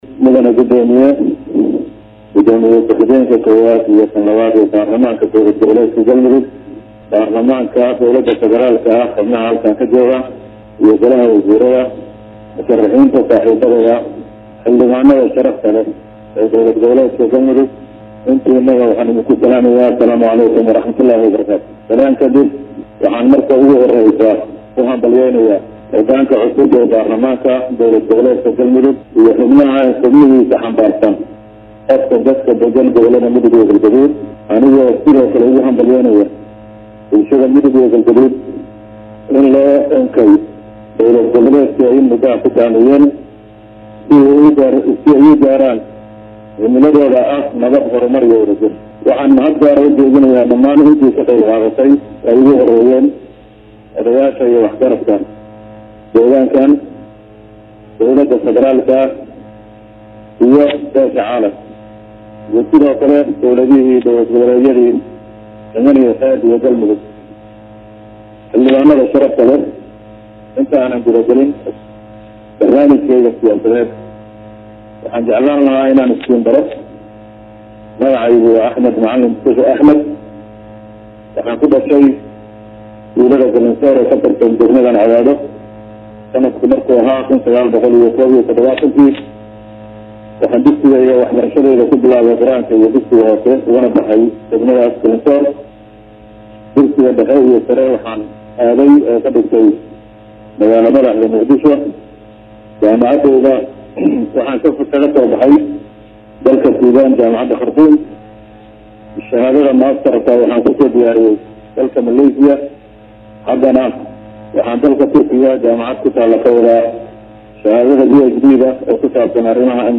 TOOS-U-DHAGEYSO_-Khudbada-Musharax-Axmed-Macalin-Fiqi-uu-ka-hor-jeediyay-Baarlamaanka-Cusub-ee-Maamulka-Galmudug-_.mp3